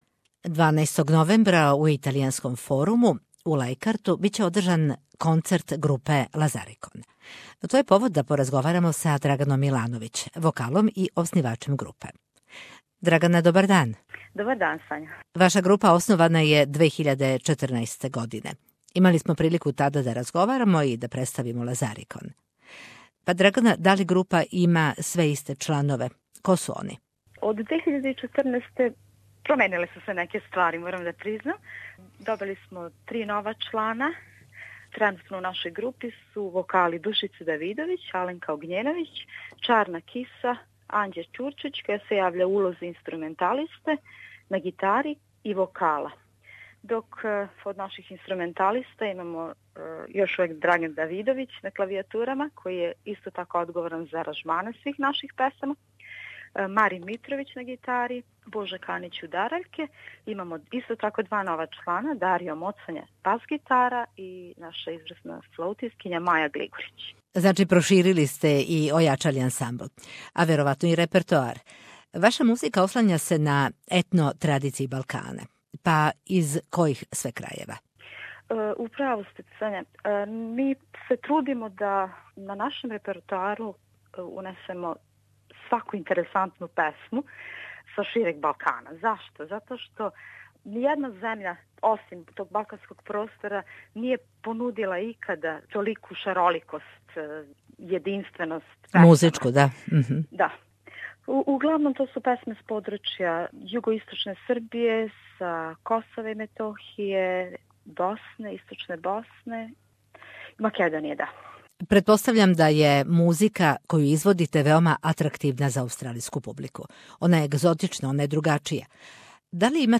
СБС на српском